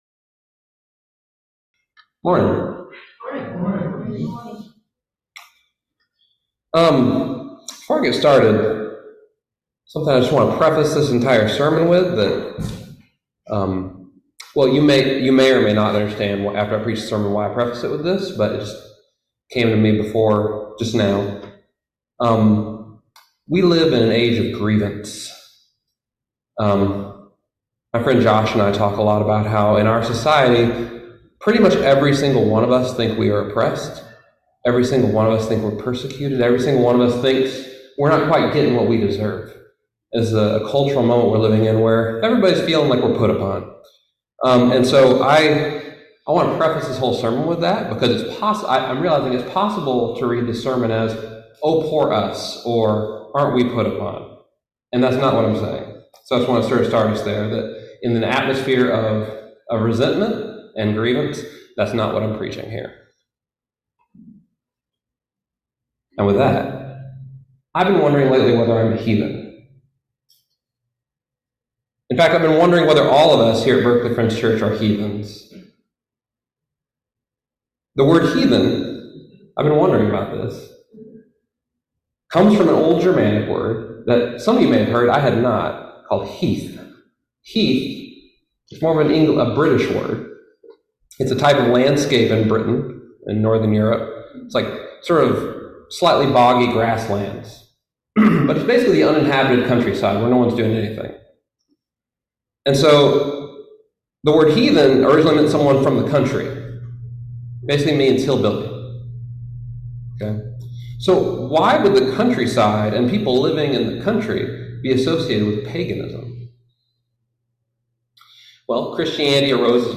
Listen to the most recent message from Sunday worship at Berkeley Friends Church, “Do Not Be Intimidated.”